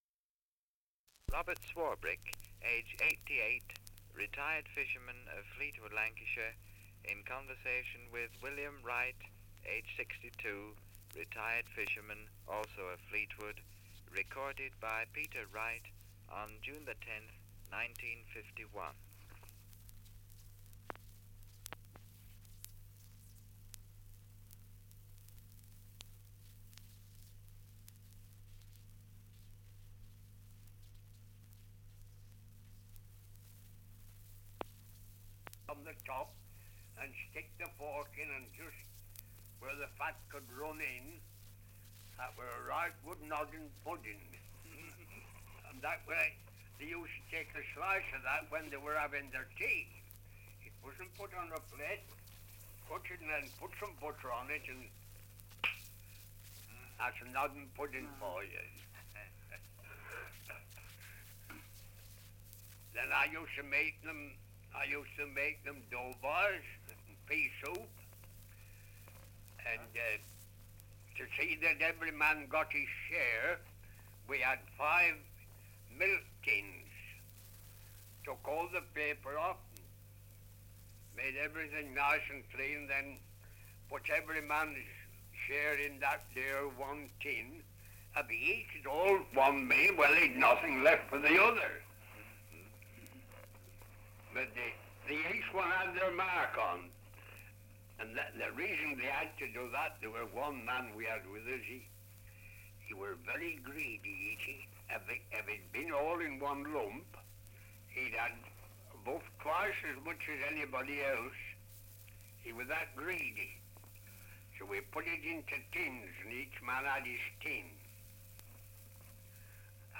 Survey of English Dialects recording in Fleetwood, Lancashire
78 r.p.m., cellulose nitrate on aluminium